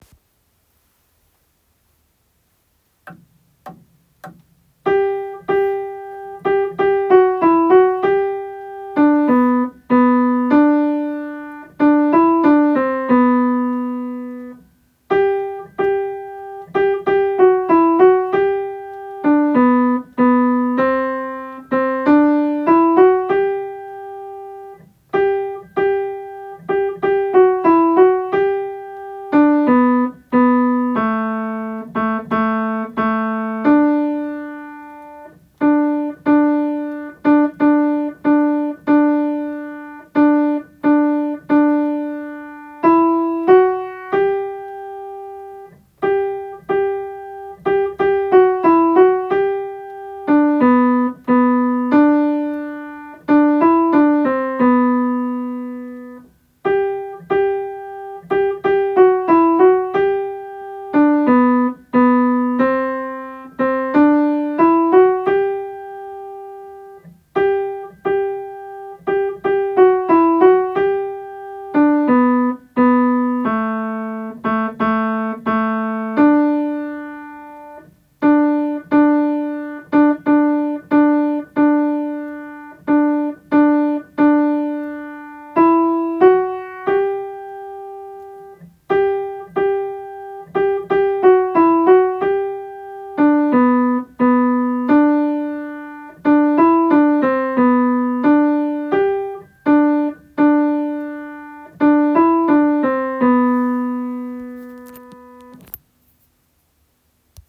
昭和の校歌　アルト　2番から　（前奏部、姫の御名こそかぐはしや～）